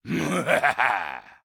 beyond/Assets/Sounds/Enemys/Male/laugch2.ogg at main
laugch2.ogg